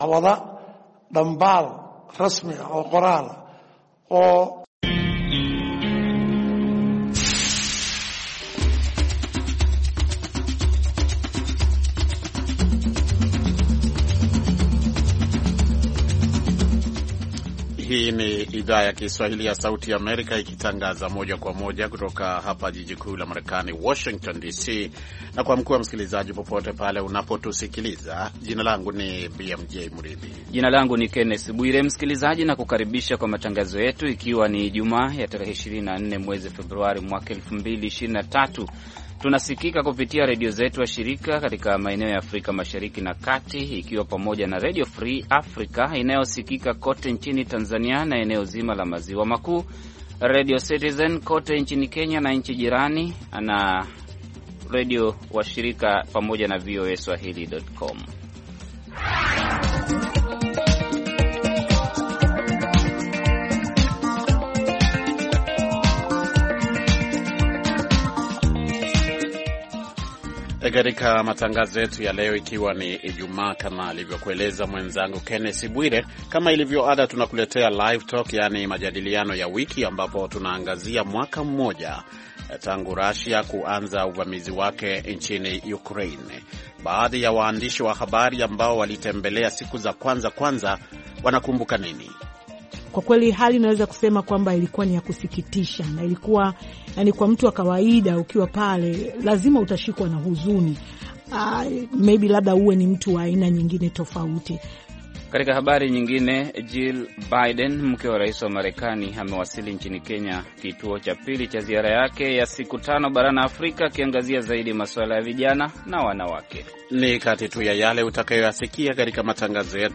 Live Talk: Wachambuzi na wasikilizaji wajadili hali ilivyo mwaka mmoja tangu vita vya Ukraine kuanza